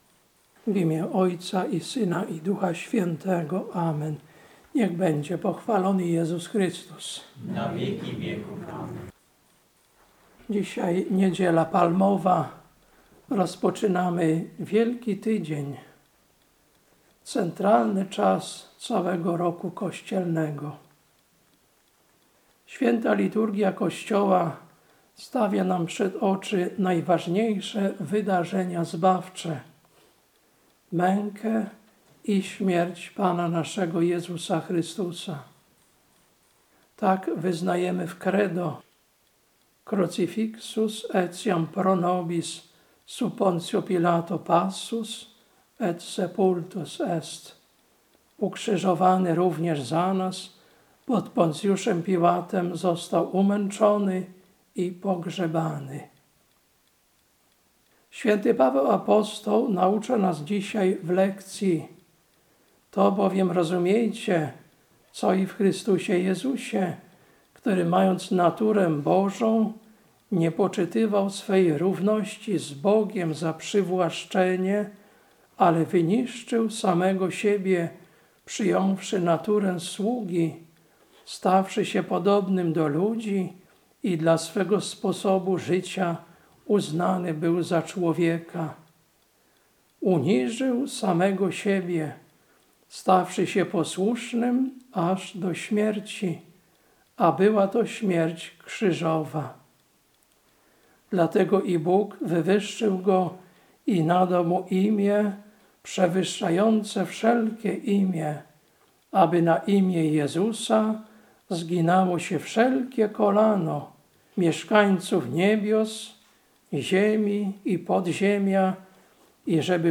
Kazanie na Niedzielę Palmową, 13.04.2025